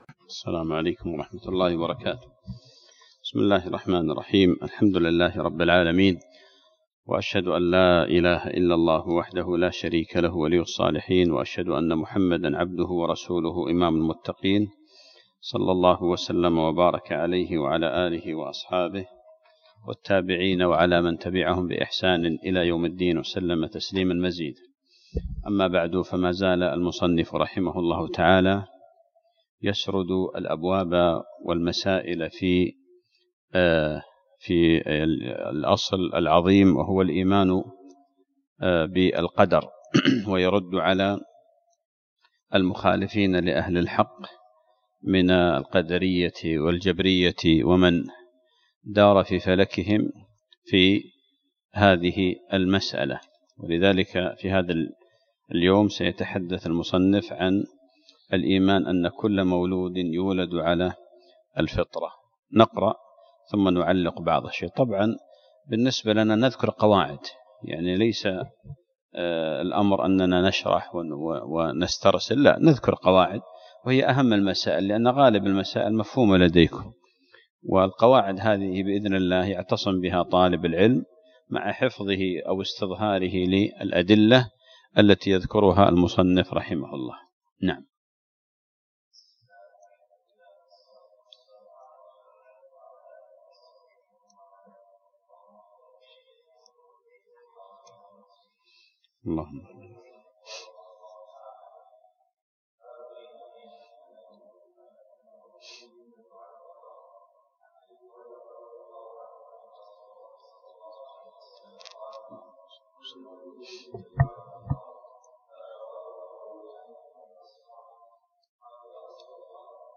الدرس الثامن عشر